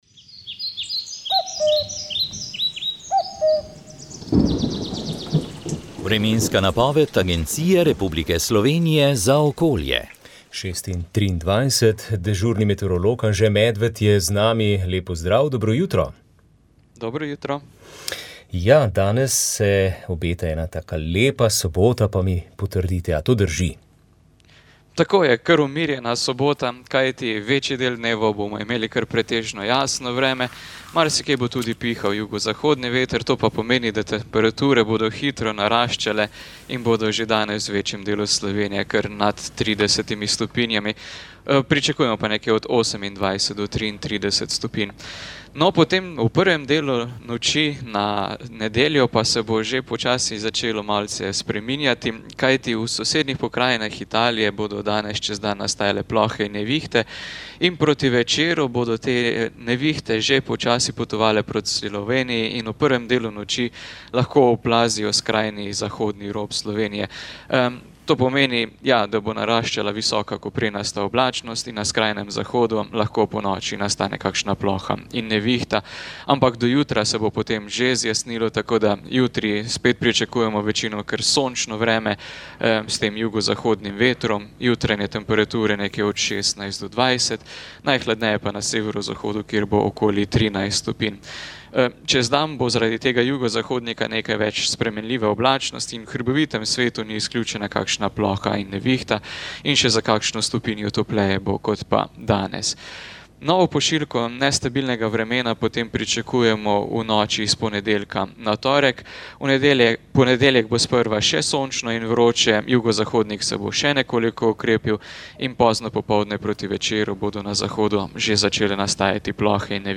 Knjigo so v četrtek 23. januarja predstavili v prostorih Gorenjskega Muzeja v Kranju.